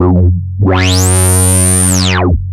OSCAR  9 F#2.wav